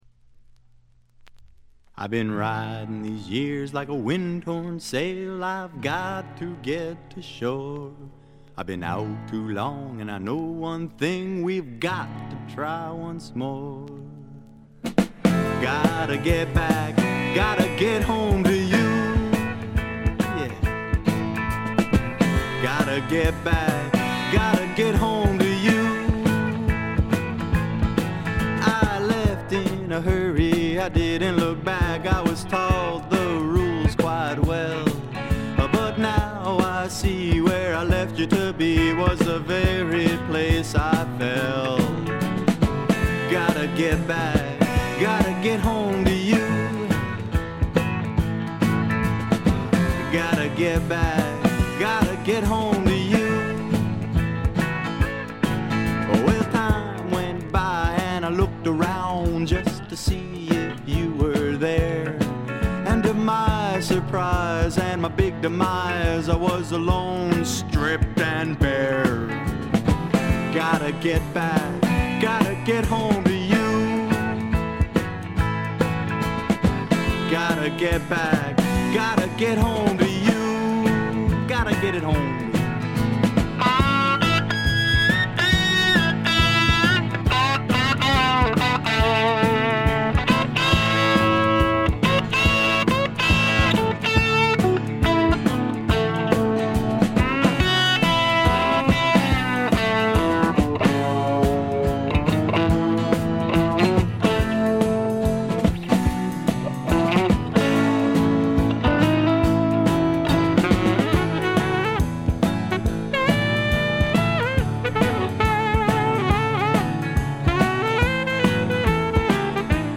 軽微なバックグラウンドノイズ、チリプチ少し。
試聴曲は現品からの取り込み音源です。